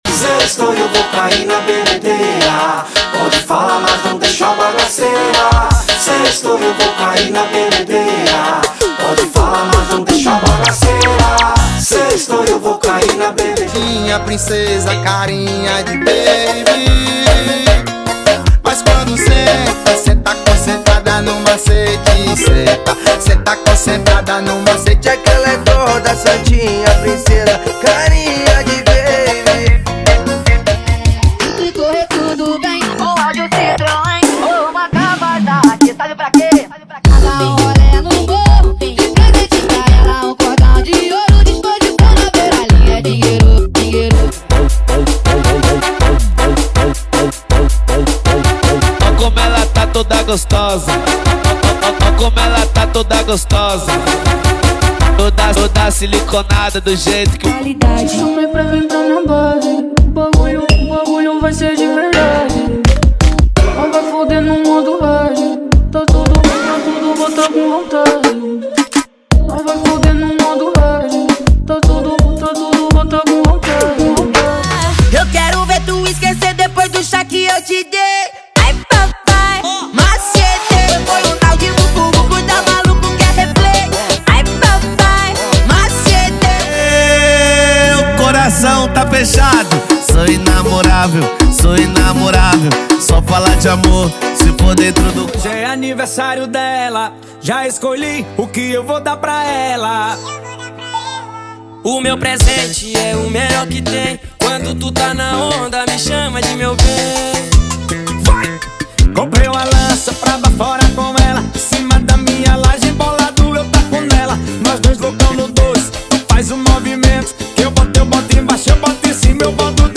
Os Melhores Brega Funk do momento estão aqui!!!
• Sem Vinhetas
• Em Alta Qualidade